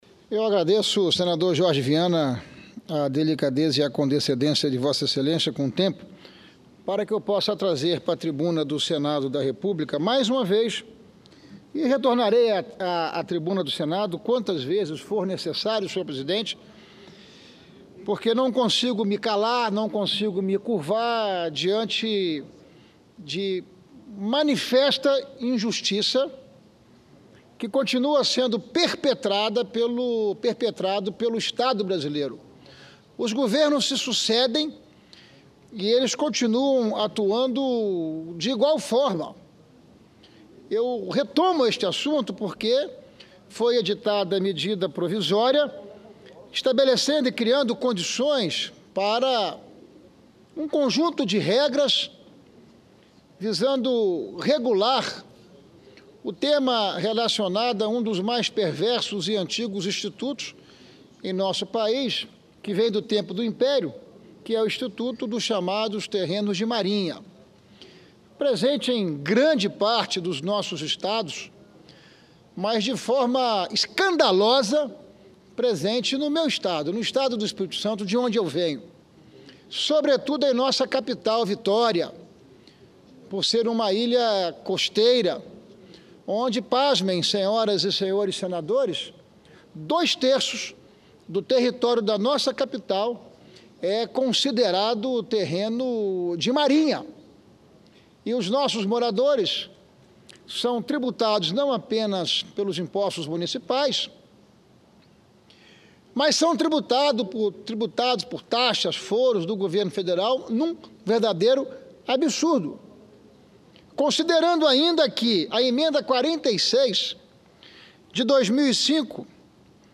Discursos Ricardo Ferraço reclama da cobranças de taxas de populações que vivem em ilhas costeiras RadioAgência Senado 16/06/2016